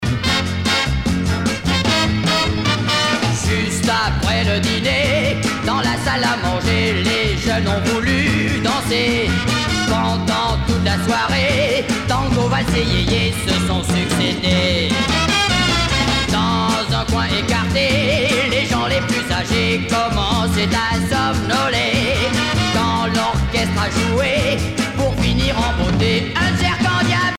danse : jerk